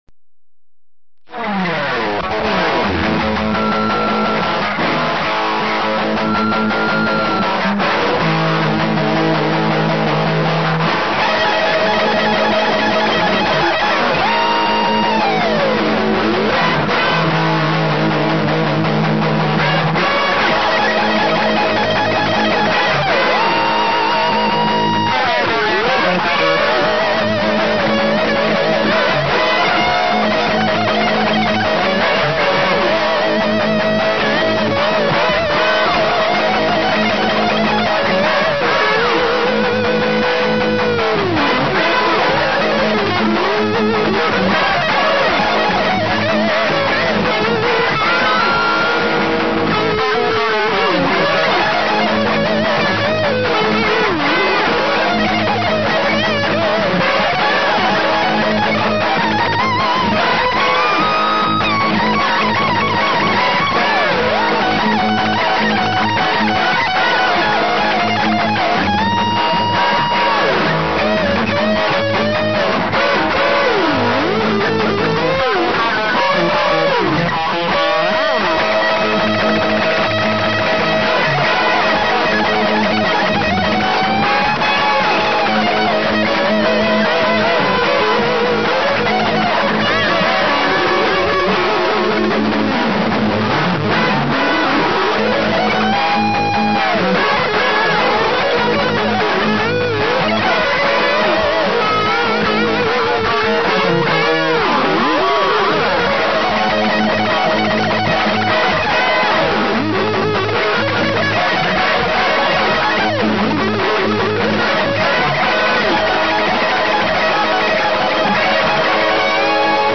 はっきり言って雑音です。 (MP3､完成度は無茶苦茶低いです)